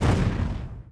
Index of /App/sound/monster2/fire_ghost